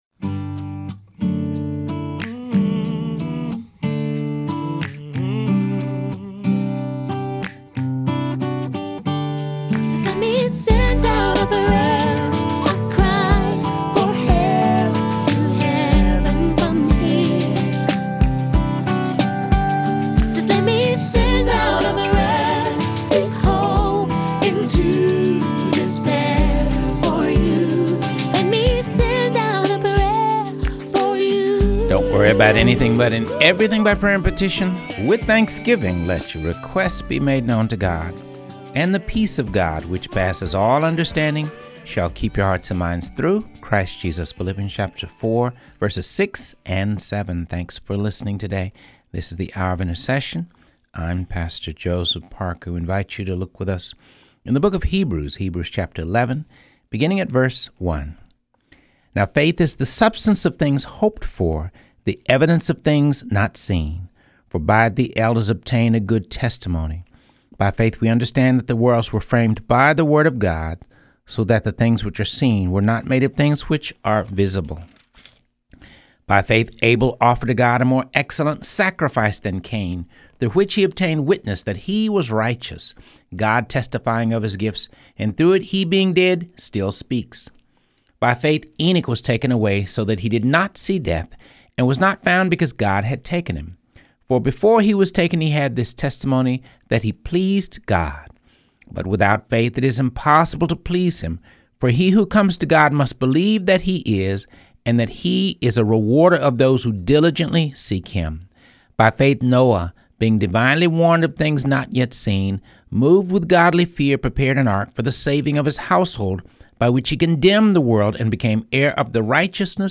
Reading through the Word of God | Episode 97